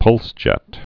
(pŭlsjĕt)